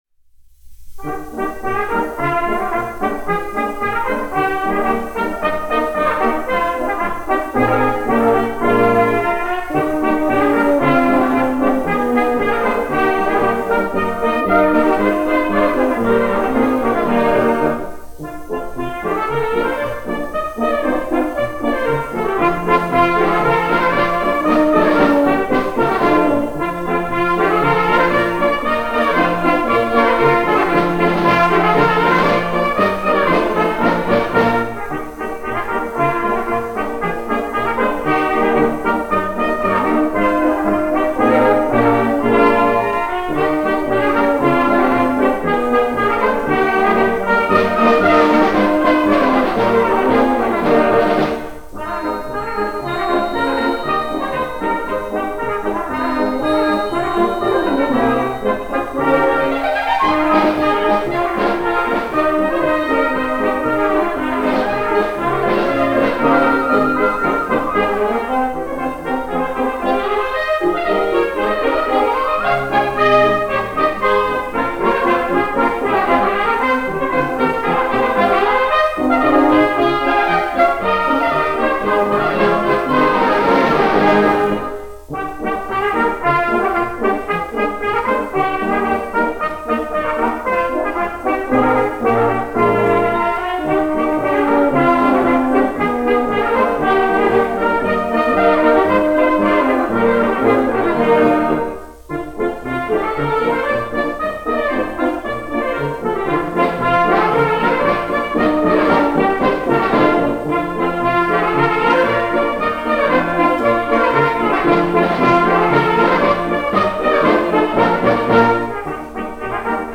1 skpl. : analogs, 78 apgr/min, mono ; 25 cm
Polkas
Pūtēju orķestra mūzika
Skaņuplate